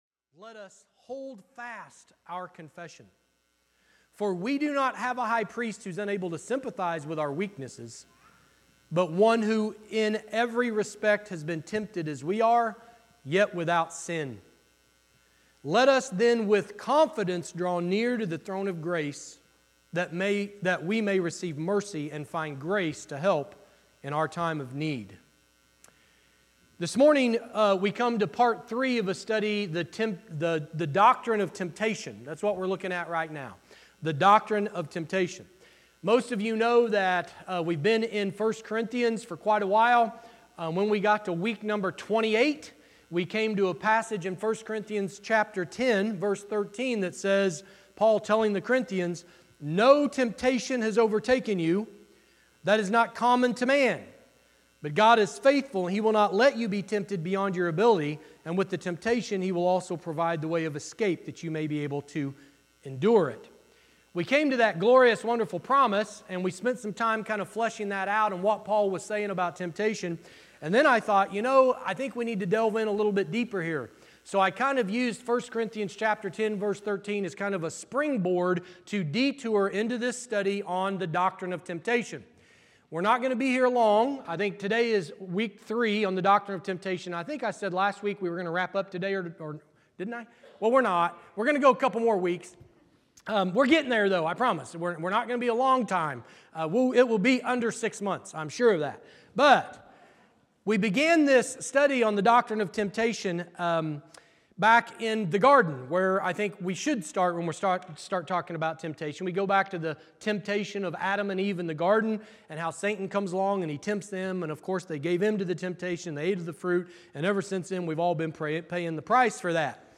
Sermon Series: Doctrine of Temptation